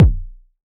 RDM_TapeB_SY1-Kick01.wav